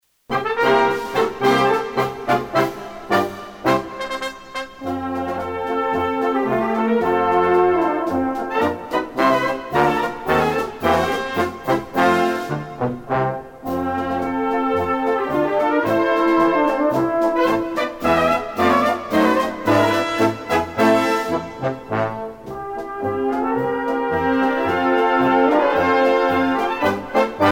danse : polka